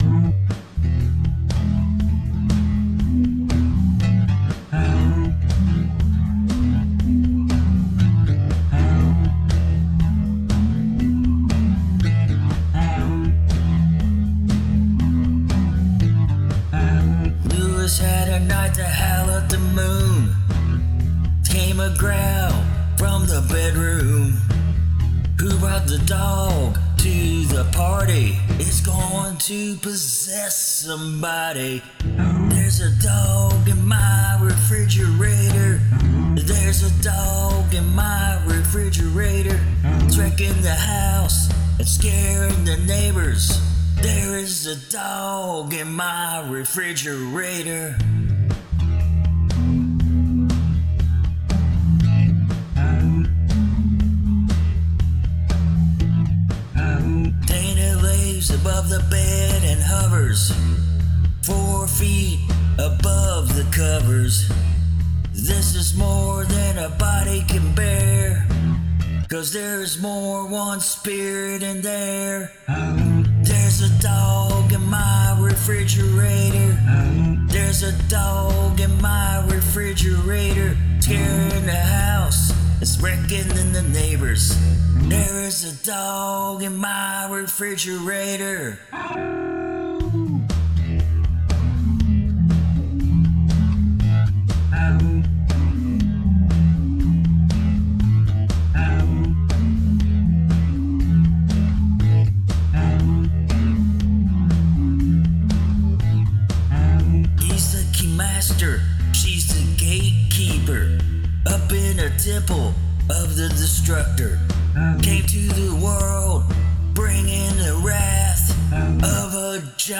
There are mistakes.
Great howls, and the the "aah-oohs" add a lot of interest.
Funny and a good beat!